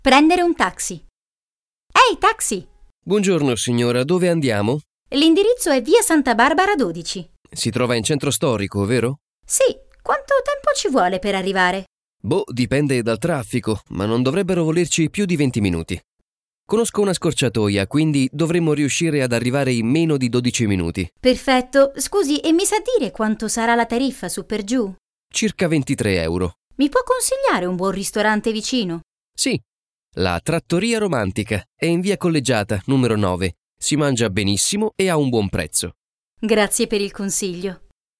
HASZNOS PÁRBESZÉD: Prendere un taxi – Utazás taxival